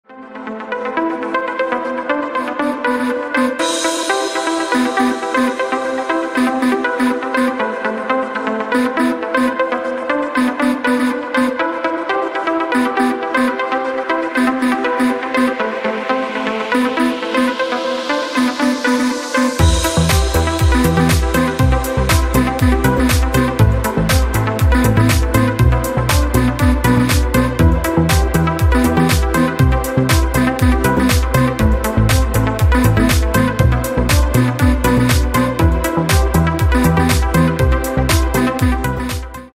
Рингтоны Без Слов
Рингтоны Электроника